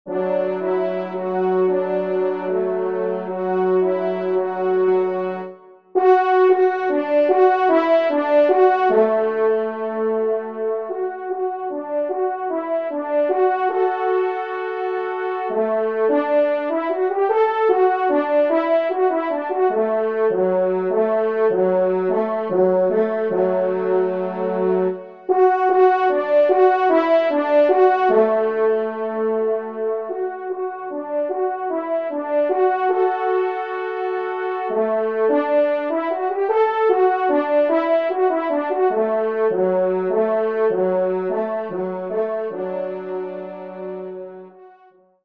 Genre :  Divertissement pour Trompes ou Cors en Ré et Picolo
2e Trompe